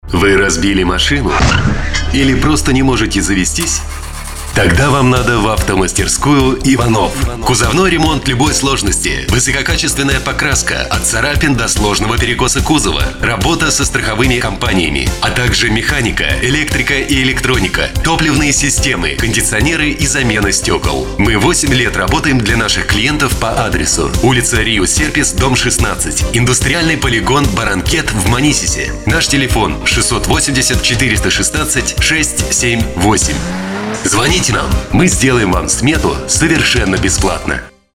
Ролик для испанского RADIO RUSA [ Автосалон IVANOFF ] Категория: Аудио/видео монтаж